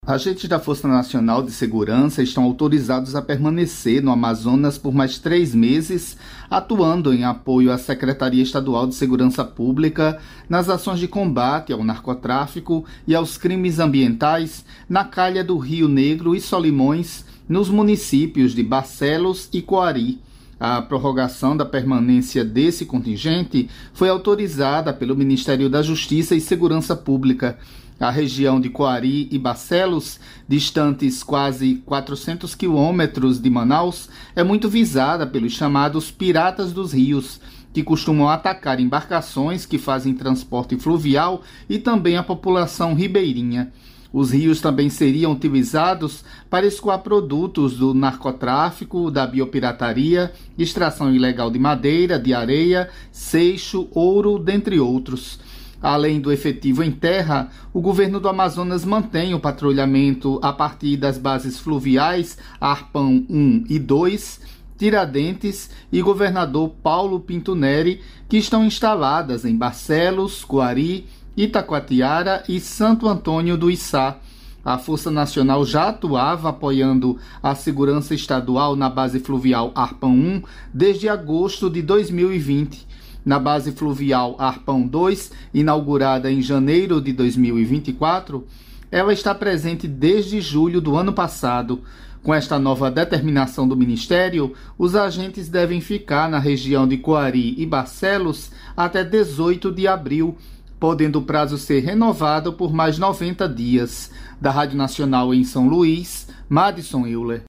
Especialistas ouvidos pela Rádio Nacional comentaram convites feitos a lideranças políticas mundiais, associados à direita internacional, e falaram sobre a participação de bilionários do setor da tecnologia. Historicamente, os EUA não costumam convidar chefes de estados de outros países.